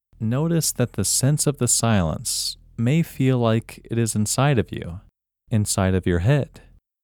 WHOLENESS English Male 5
WHOLENESS-English-Male-5.mp3